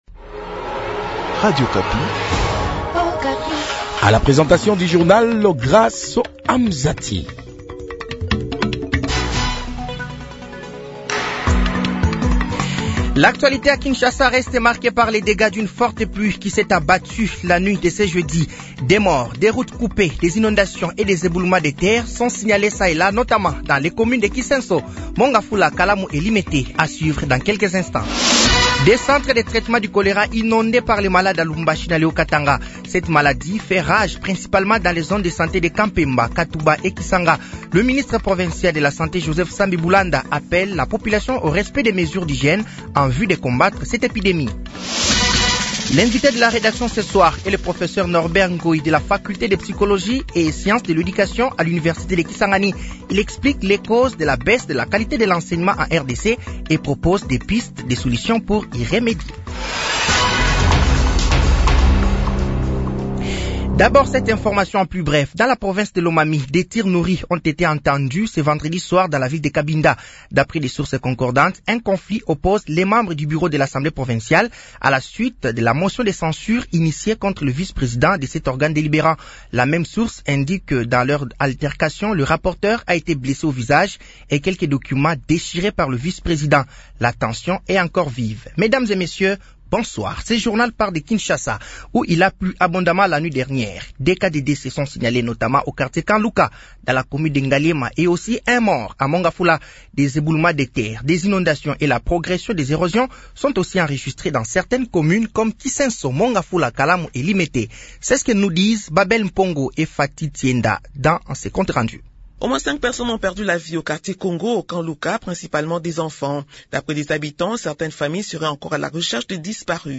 Journal français de 18h de ce vendredi 02 mai 2025